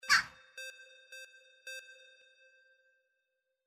Crow Jackdaw Forest 04
Stereo sound effect - Wav.16 bit/44.1 KHz and Mp3 128 Kbps
previewANM_CROW_FORREST_WBHD04.mp3